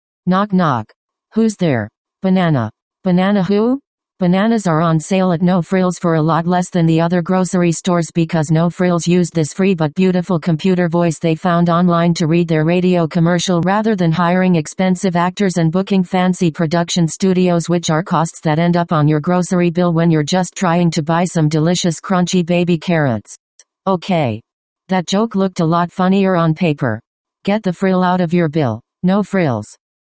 Because the grocery retailer is all about saving customers money, it skipped most of those steps and used a cold robotic voice (think Siri’s less famous cousin) to narrate the scripts. The voice makes attempts at a knock-knock joke and some rather poetic narration in an attempt to seem a bit more engaging, but at the end of the day it’s all about keeping the frills off the bill.